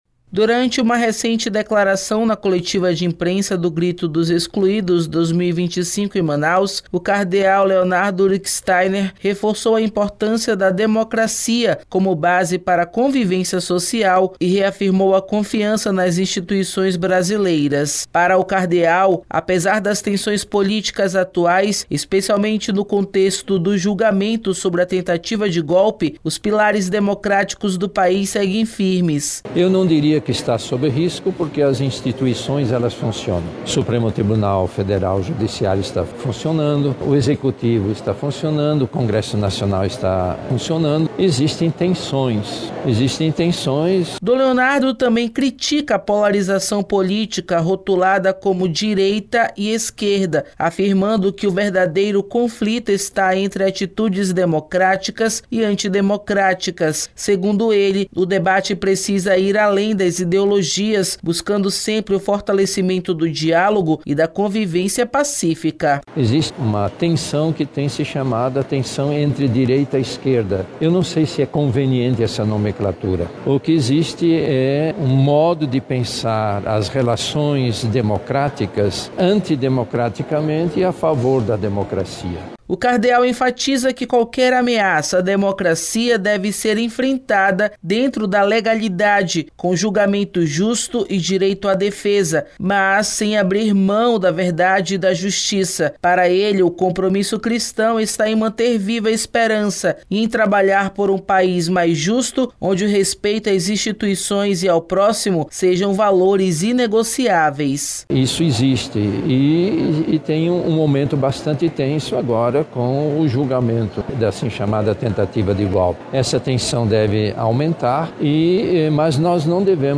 Durante coletiva de imprensa do Grito dos Excluídos 2025 em Manaus (AM), Cardeal Leonardo Steiner reforçou a importância da democracia como base para a convivência social e reafirmou a confiança nas instituições brasileiras.